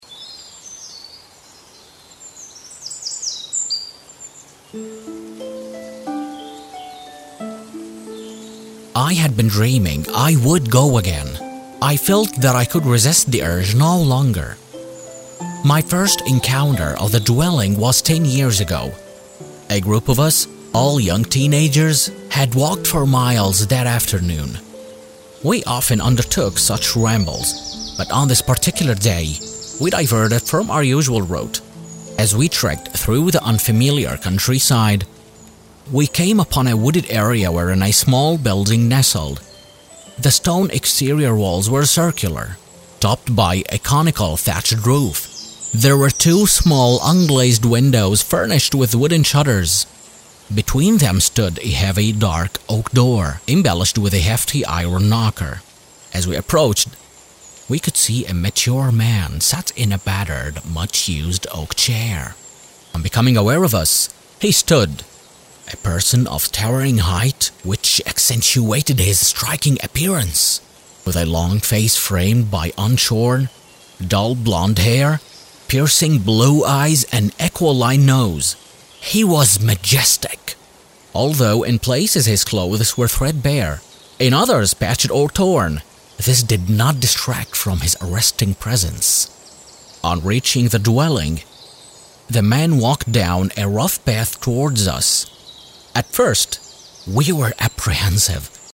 Male
English (North American)
Adult (30-50)
Believable , Bold , Calming , Caring , Commanding , Energized , Comic , Narrator
Audiobooks
1113The_man_in_the_woods_-_Audiobook_1.mp3